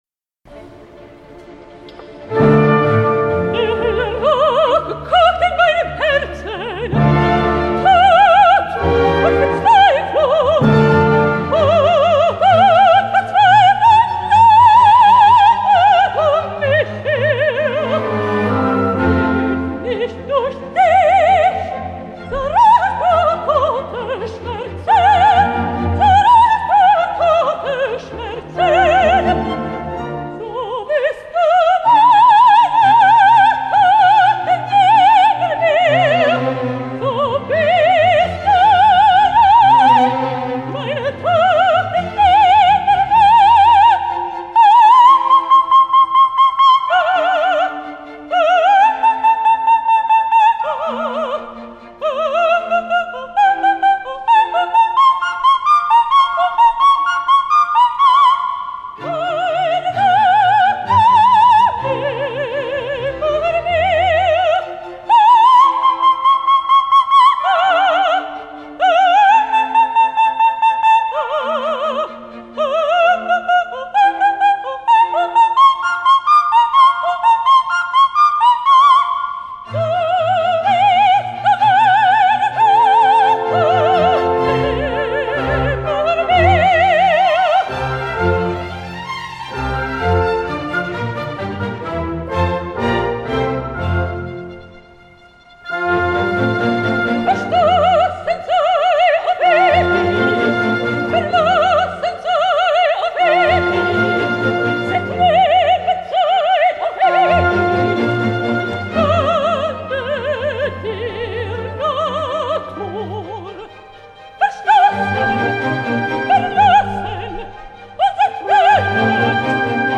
dramatic coloratura soprano
from 'Die Zauberflote' Mozart 'Shock of the New', Sydney Opera House, October 2005, with Sydney Symphony Orchestra, conducted by Gianluigi Gelmetti (live broadcast by ABC).